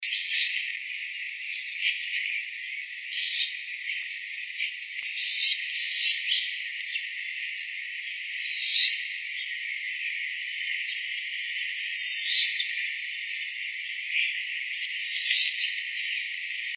Southern House Wren (Troglodytes musculus)
Life Stage: Adult
Location or protected area: Reserva Ecológica Costanera Sur (RECS)
Condition: Wild
Certainty: Recorded vocal